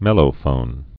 (mĕlō-fōn)